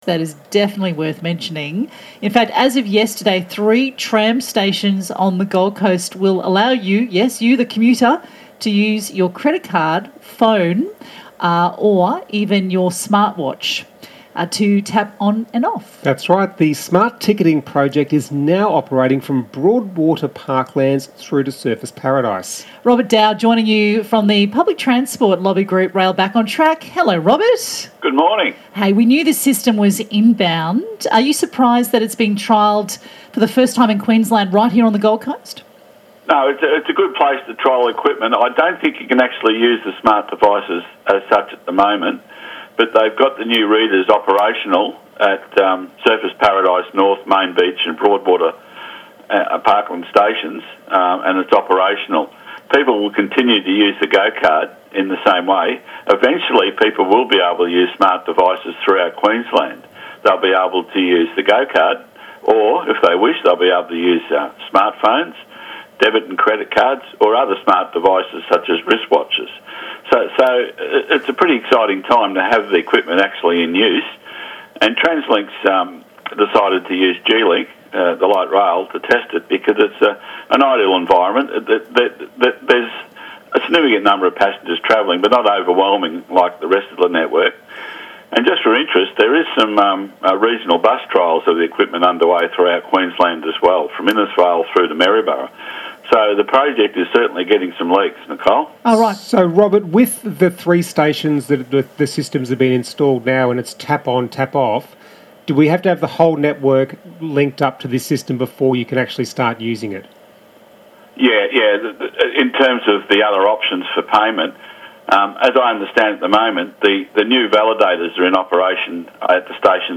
Been booked for a short interview on Smart Ticketing ABC Gold Coast radio and 6.40am today.